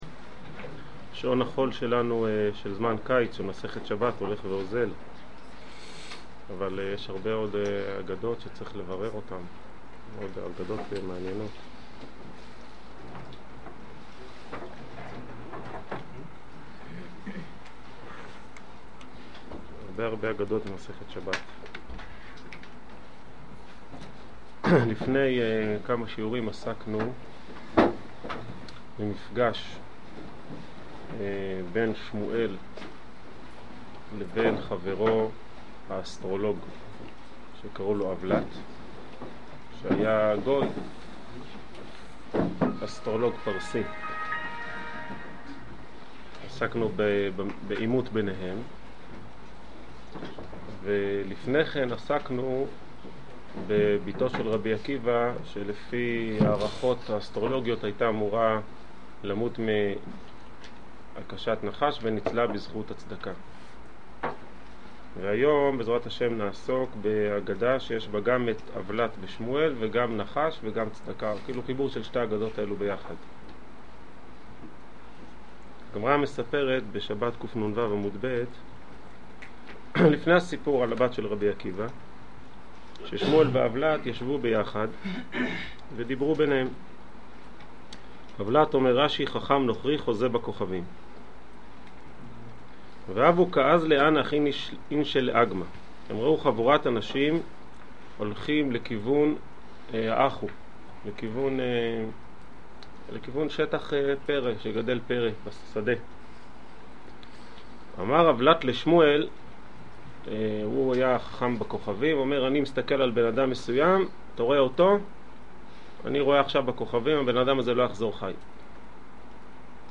יציאה מהגלות בזכות הערבות | שיעור כללי - בני דוד - עלי